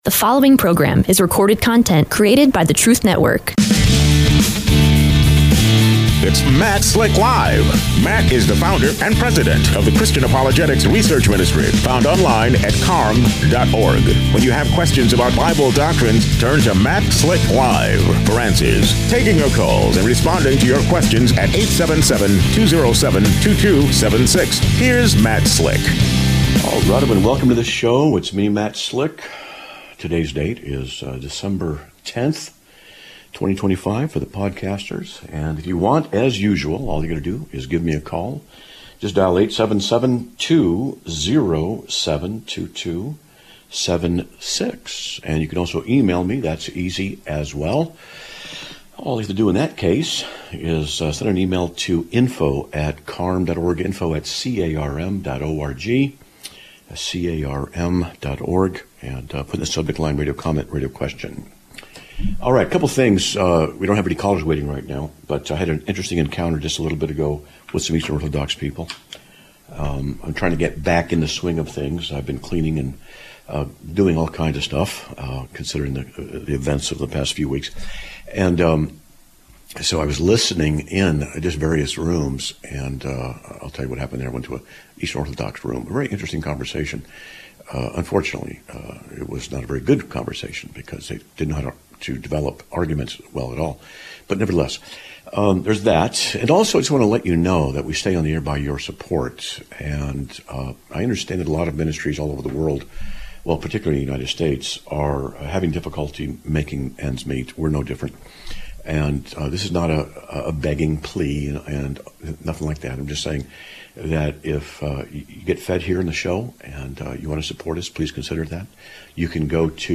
Live Broadcast of 12/10/2025